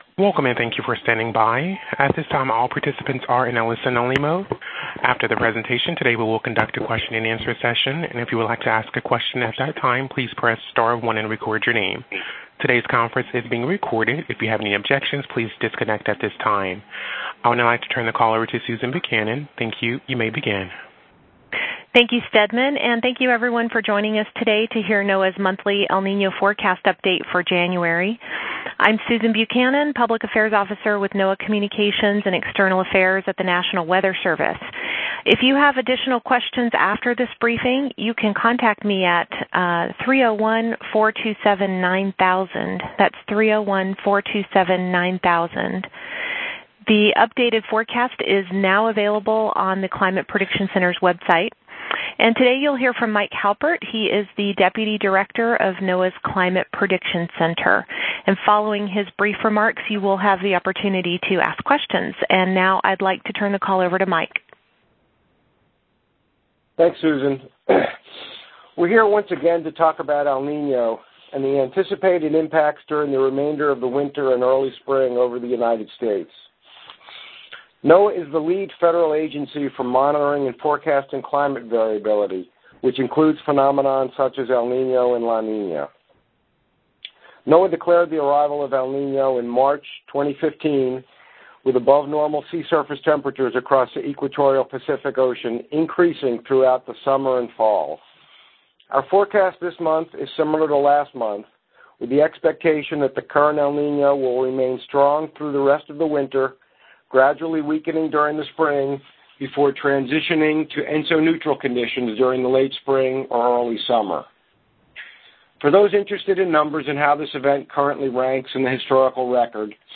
Media Teleconference: NOAA climate forecaster to discuss status of El Niño